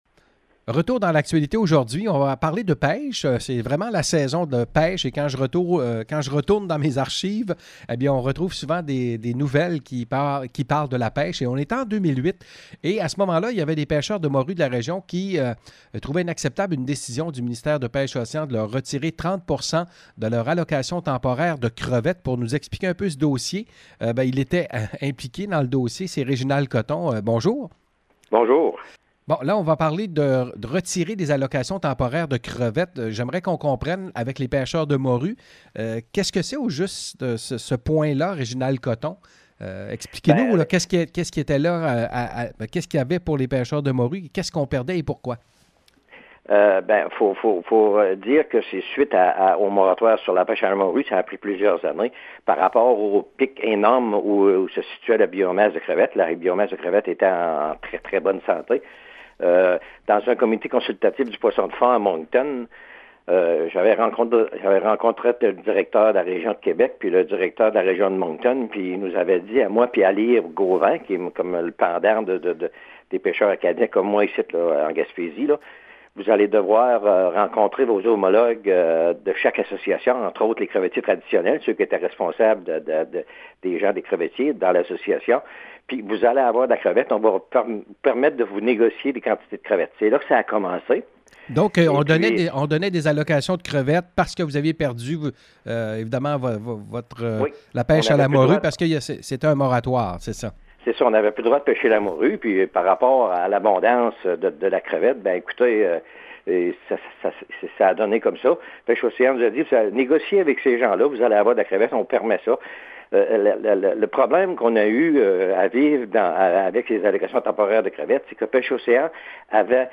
Retour en 2008 sur les pêcheurs de poissons de fonds mécontents de la décision de Pêches et Océans. Entrevue